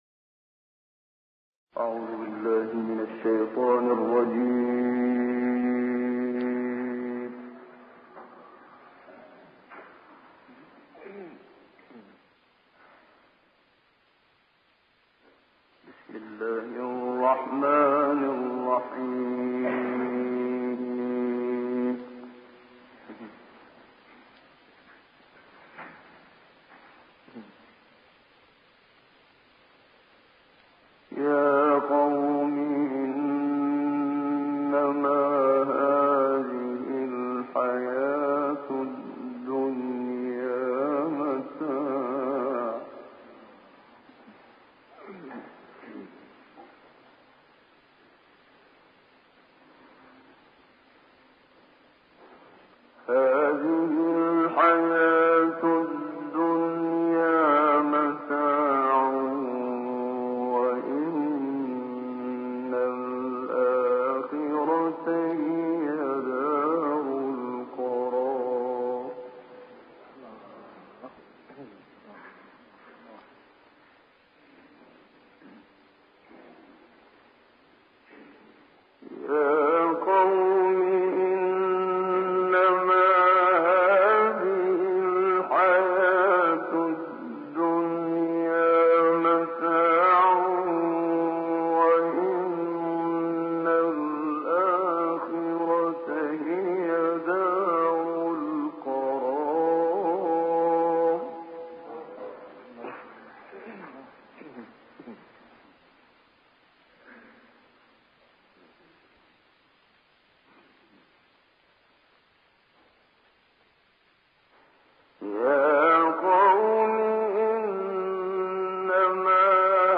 توضیح اینکه، منشاوی در ابتدای تلاوت همین یک آیه را مجموعاً 9 بار در مقام‌های بیات و رَست تلاوت می‌کند.
سپس با لطافت برای بار سوم آیه را تکرار می‌کند و شنونده را اندرز می‌دهد.
در مرتبه پنجم، منشاوی نغمه‌ای از بیات را انتخاب می‌کند که در آن بویی از جمع‌بندی از یک بحث به مشام می‌رسد.
سپس برای بار ششم آیه را تلاوت می‌کند و در این نوبت وارد مقام رست می‌شود.
در مرتبه هفتم لحن منشاوی مجدداً حالت التماس و خواهش‌گونه می‌گیرد و گویی از مخاطب با تمام وجود می‌خواهد که دنیا را کالایی بداند و اصالت را به آخرت بدهد.